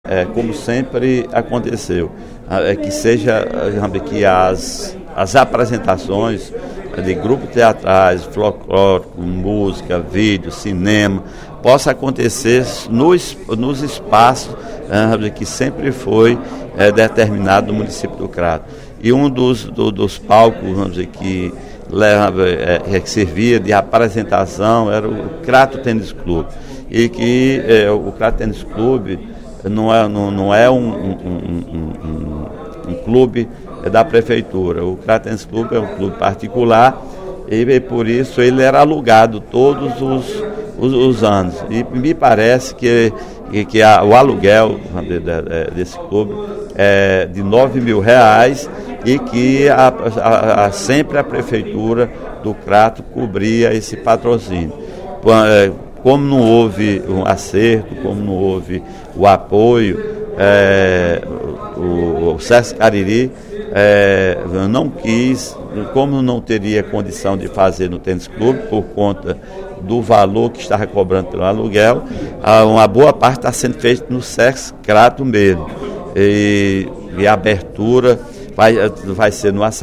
No primeiro expediente desta sexta-feira (08/11), o deputado Sineval Roque (Pros) lamentou a saída do Crato da abertura da 15ª Mostra Sesc Cariri, que será realizada a partir de hoje até o dia 13, na região sul do Estado.